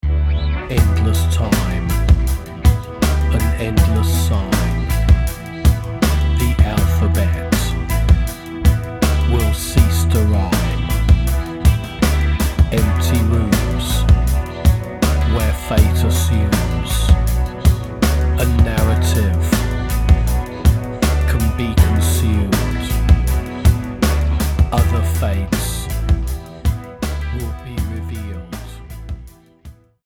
The results cohere into a sonic juggernaut.